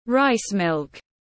Sữa gạo tiếng anh gọi là rice milk, phiên âm tiếng anh đọc là /raɪs mɪlk/